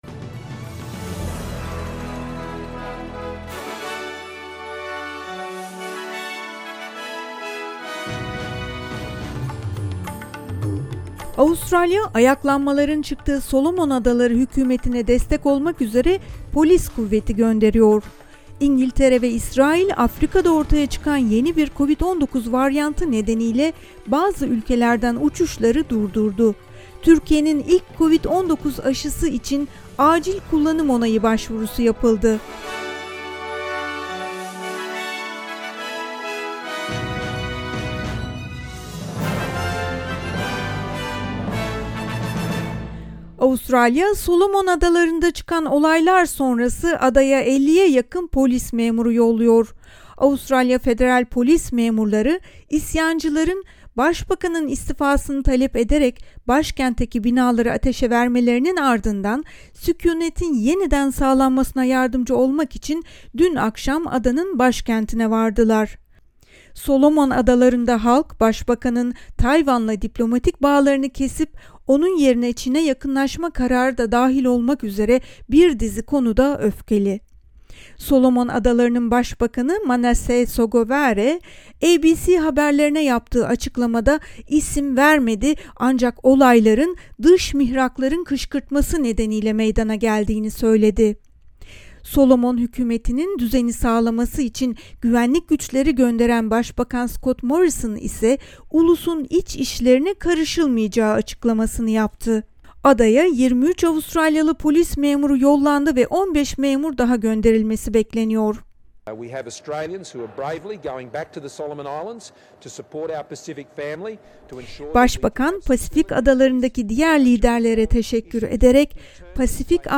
SBS Türkçe Haberler 26 Kasım